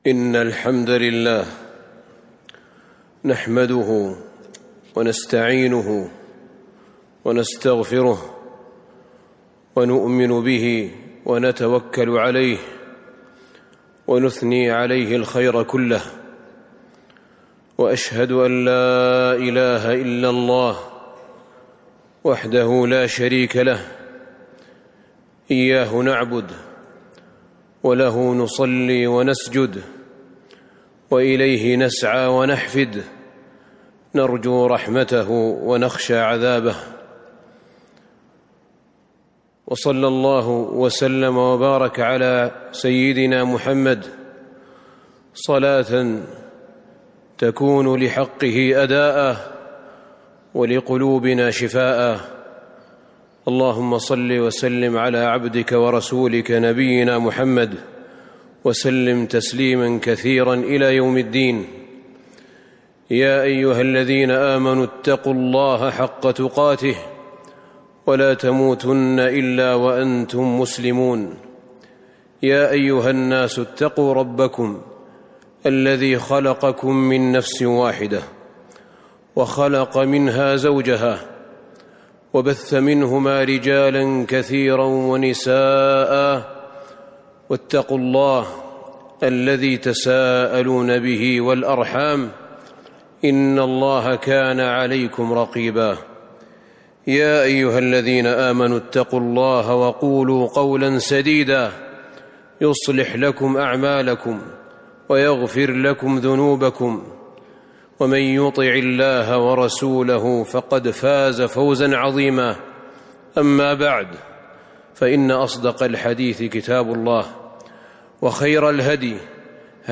تاريخ النشر ٥ شوال ١٤٤٣ هـ المكان: المسجد النبوي الشيخ: فضيلة الشيخ أحمد بن طالب بن حميد فضيلة الشيخ أحمد بن طالب بن حميد الكلمة الطيبة